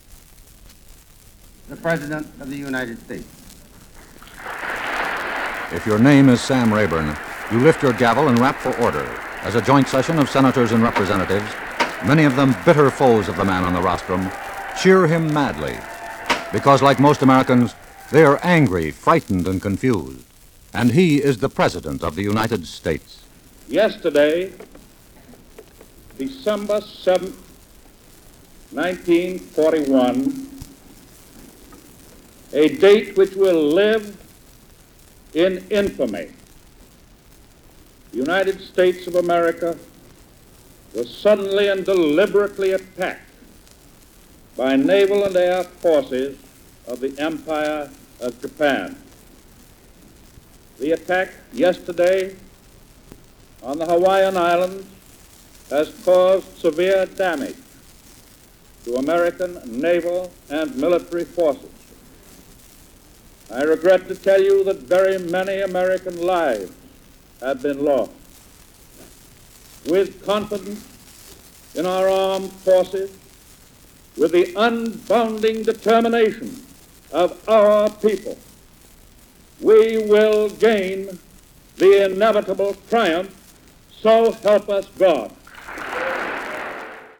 roosevelt declares war on japan.mp3